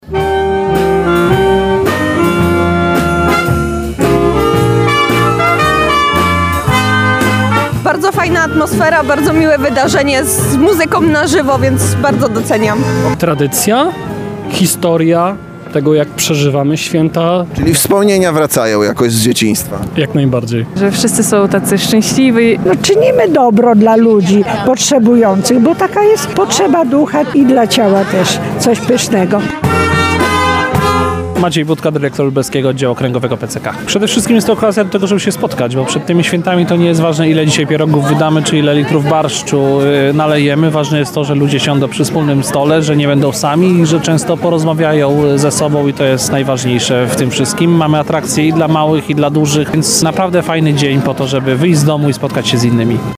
Mieszkańcy Lublina i turyści zasiedli do świątecznego stołu. Okazją do tego jest spotkanie integracyjne w Lublinie organizowane przez Polski Czerwony Krzyż.